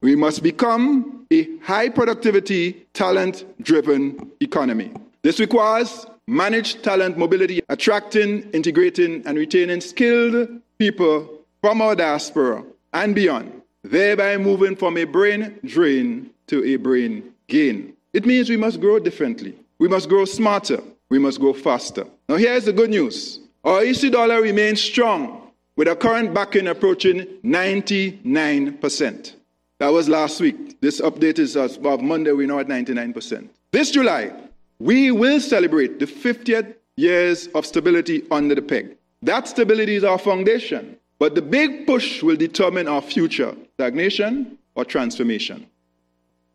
Regional leaders and development partners convened for the 10th Annual Growth and Resilience Dialogue, hosted by the Eastern Caribbean Central Bank (ECCB), in Basseterre, St. Kitts, to discuss strategies for strengthening economic growth and resilience across the Eastern Caribbean Currency Union (ECCU).
ECCB’s Governor, Timothy N. J. Antoine, gave opening remarks and he highlighted that current growth levels were insufficient for meaningful transformation.